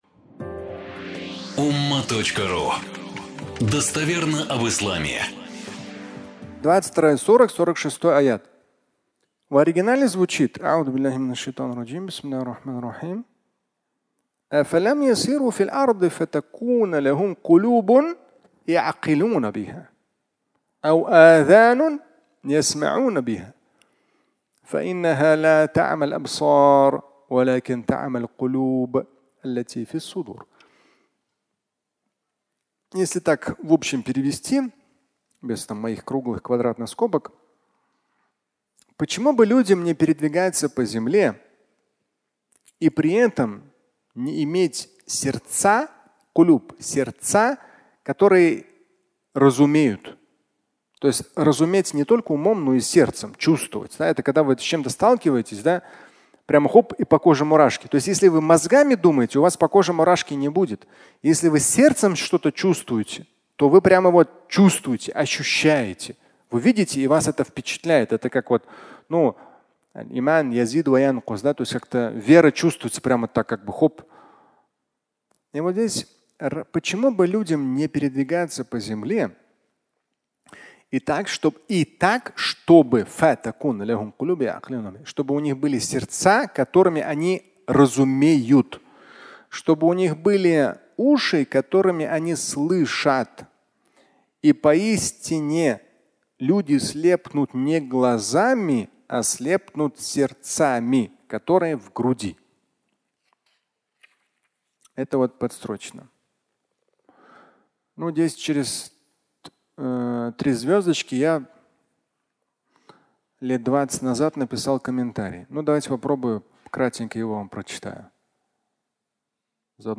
22:46 и пояснение (аудиолекция)